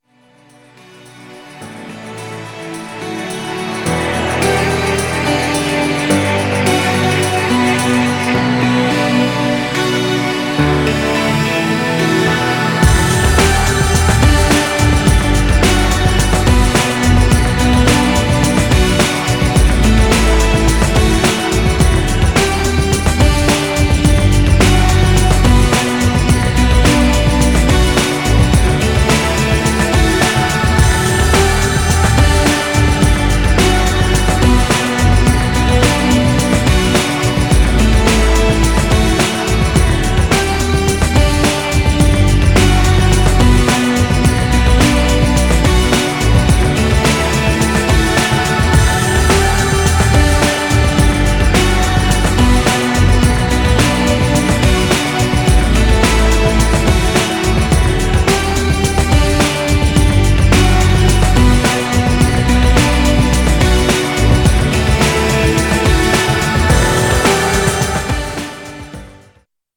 Styl: Breaks/Breakbeat